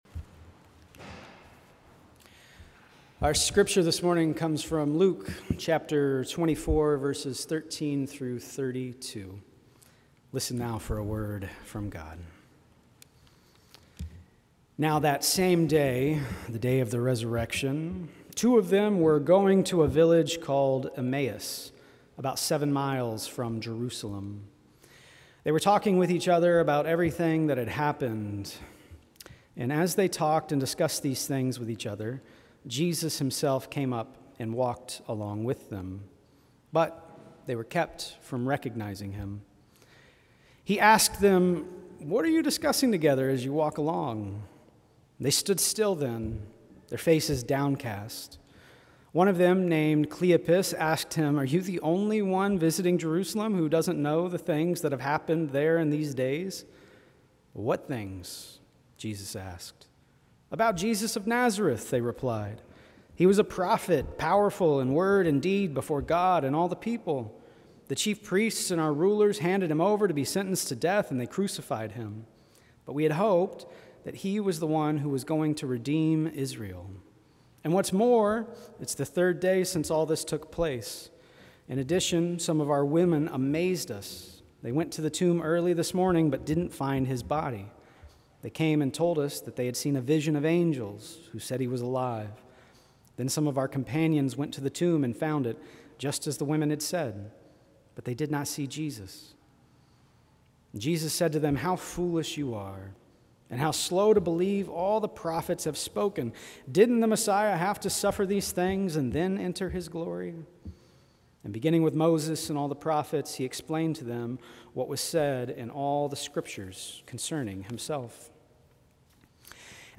Sermons | Fort Street Presbyterian Church